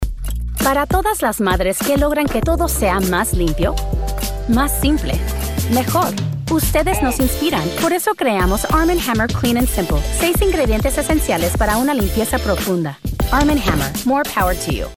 Female Voice Over, Dan Wachs Talent Agency.
Bilingual Voice Actor.  English, Neutral Spanish, Columbian and Mexican Dialects.
Commercial #1 - Spanish